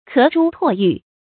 咳珠唾玉 ké zhū tuò yù
咳珠唾玉发音